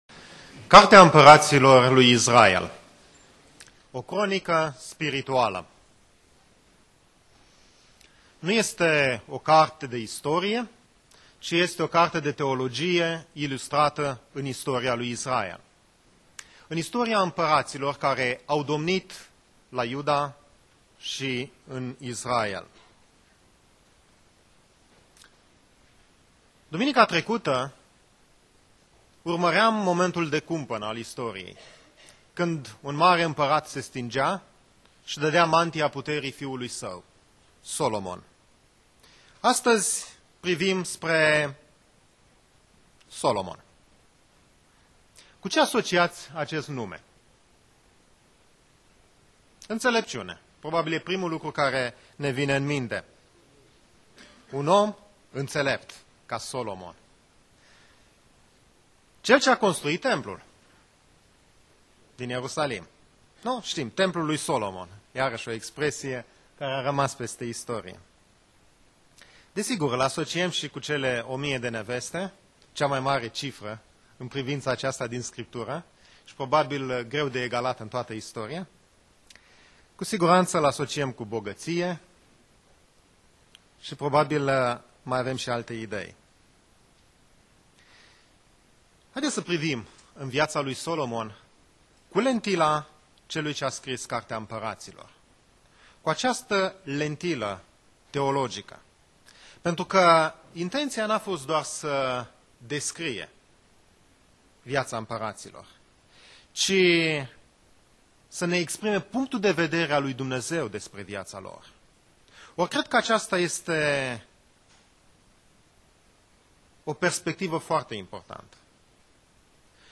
Predica Exegeza - 1 Imparati Cap 3-4